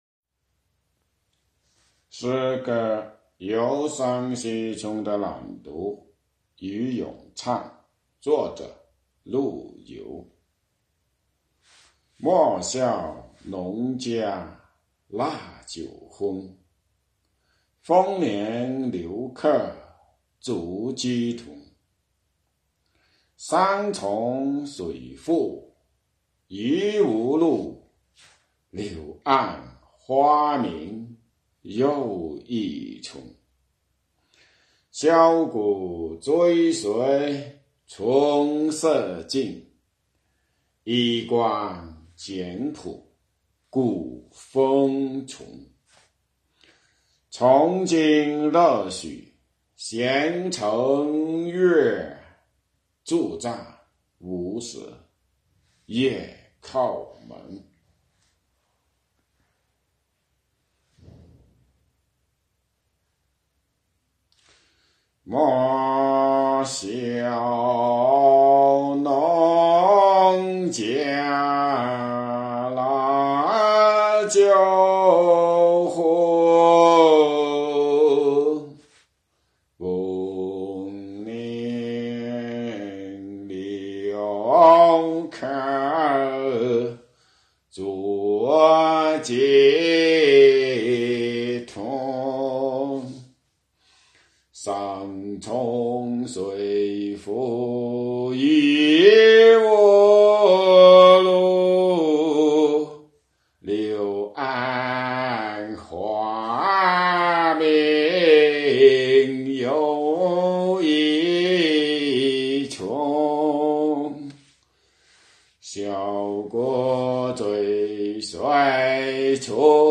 请听《游山西村》的朗读和咏唱：